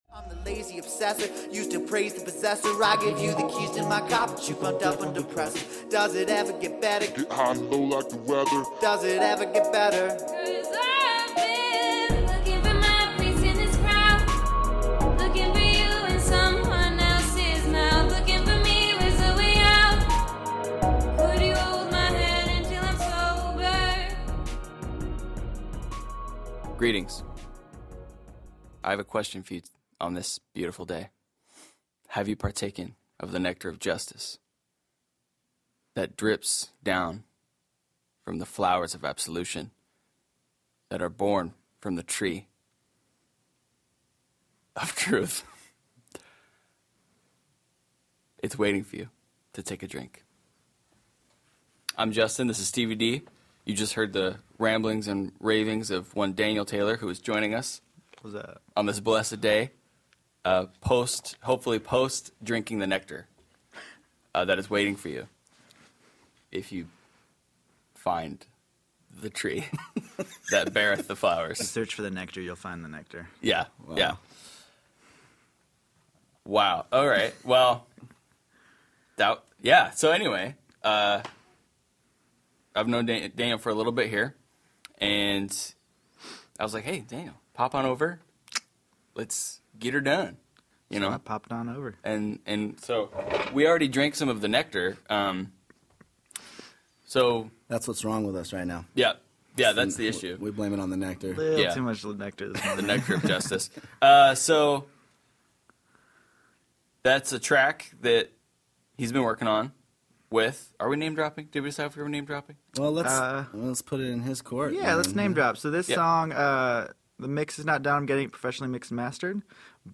Finally... after much anticipation we had our first Interview Episode!!